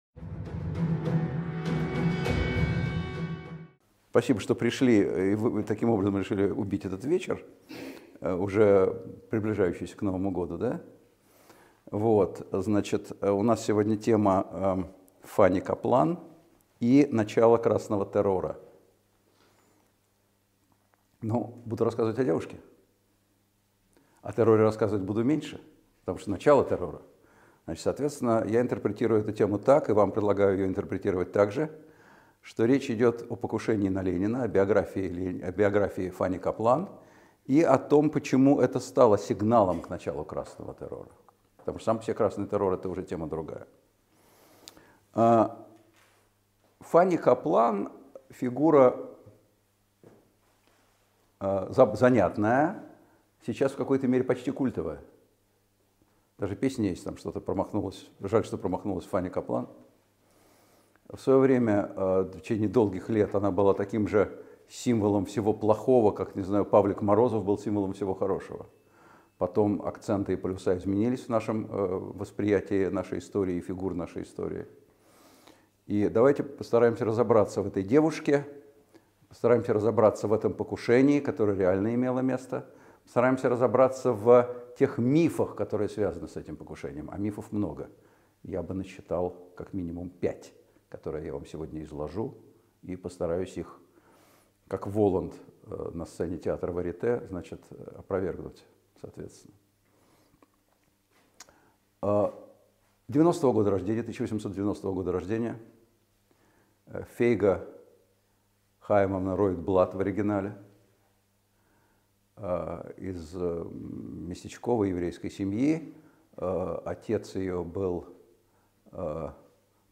Аудиокнига Покушение на Ленина: Фанни Каплан и начало Красного террора | Библиотека аудиокниг
Aудиокнига Покушение на Ленина: Фанни Каплан и начало Красного террора Автор Николай Сванидзе Читает аудиокнигу Николай Сванидзе.